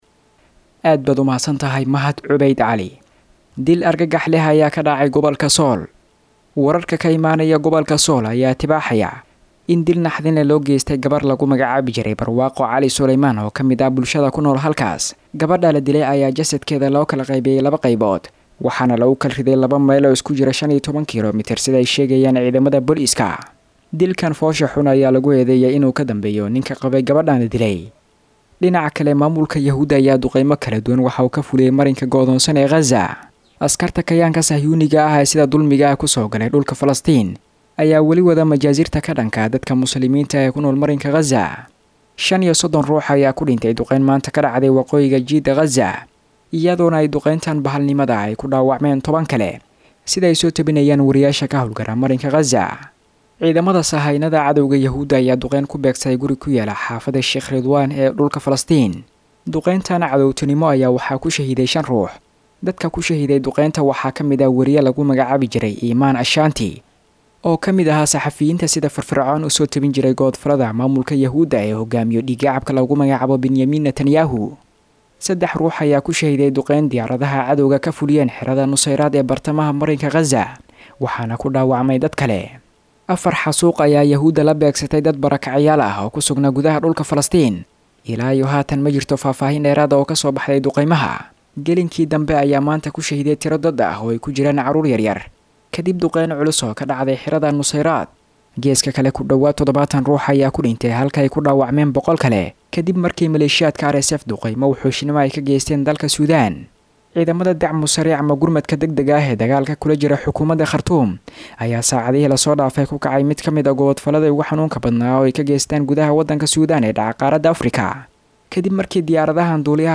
Xubinta Wararka Caalamka oo ku baxda Barnaamijka Dhuuxa Wareysiyada ee idaacadda Islaamiga ah ee Al-Furqaan, waxaa lagu soo gudbiyaa wararkii ugu dambeeyay ee daafaha caalamka.